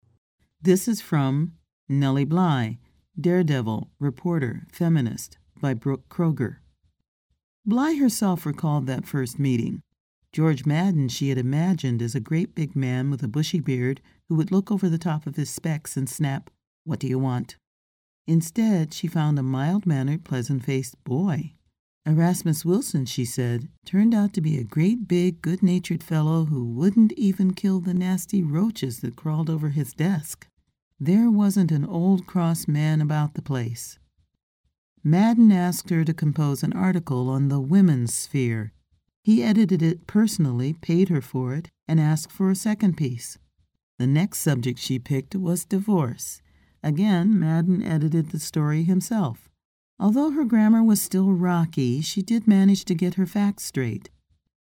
Narrator voice - intelligent, warm, conversational; broad emotional range; large repertoire of characters
Sprechprobe: Industrie (Muttersprache):